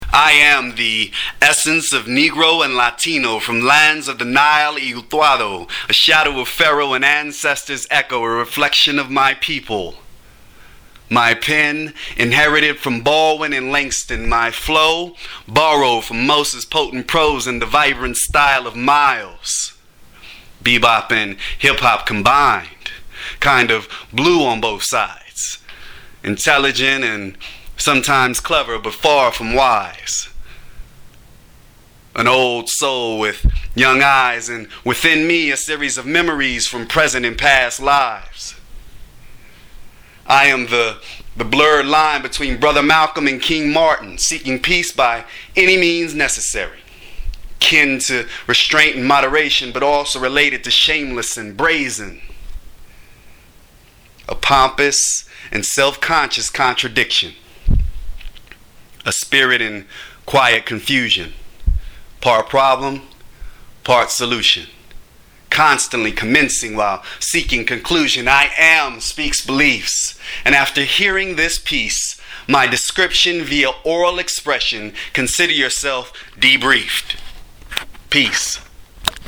play the Spoken Word version ↑